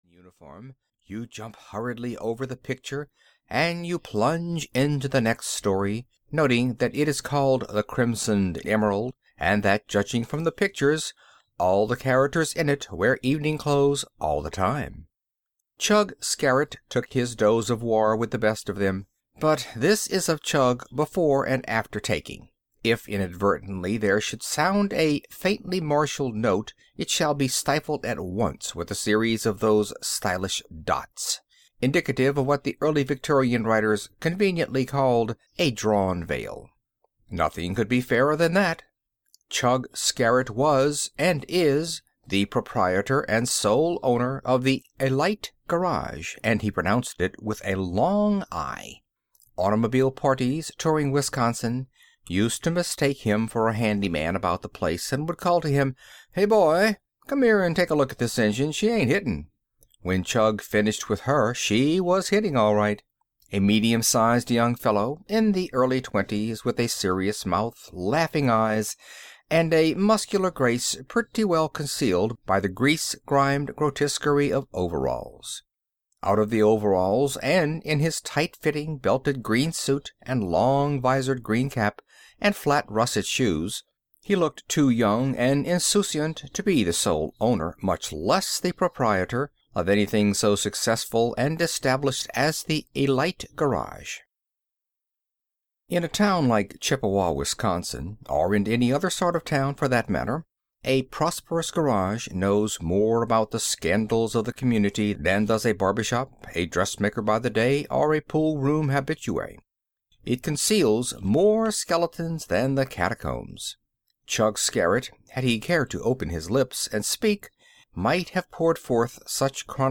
The Dancing Girls (EN) audiokniha
Ukázka z knihy